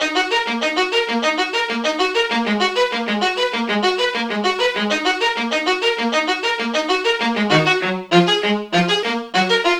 Hands Up - Rapid Strings.wav